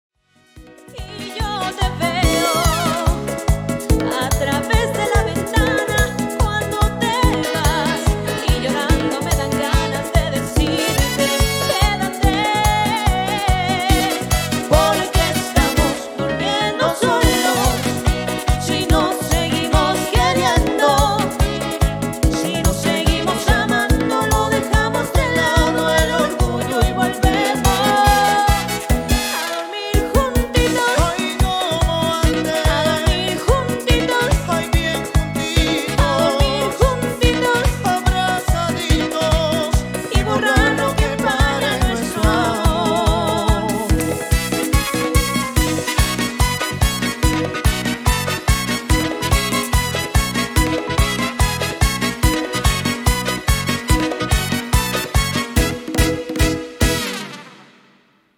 música en vivo para bodas y eventos
Canciones reales Grabadas en Vivo!
Género Cumbia